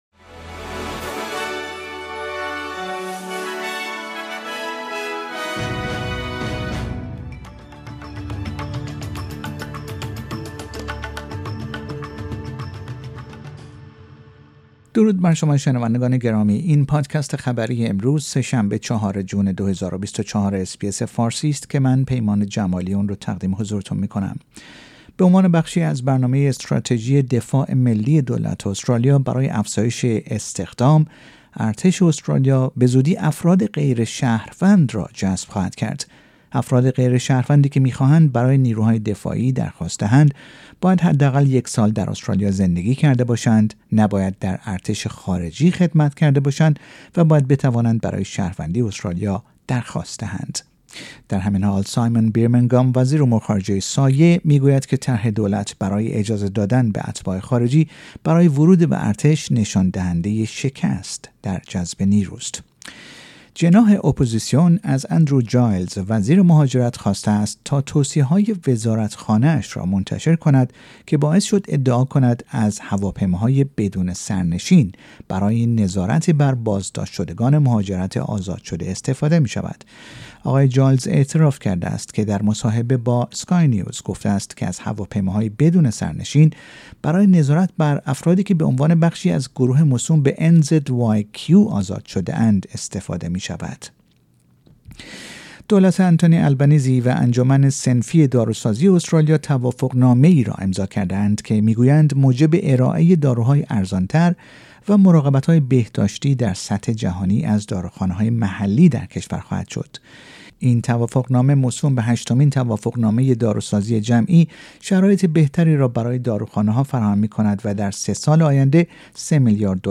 در این پادکست خبری مهمترین اخبار استرالیا، در روز سه شنبه ۴ جون ۲۰۲۴ ارائه شده است.